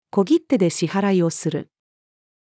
小切手で支払いをする。-female.mp3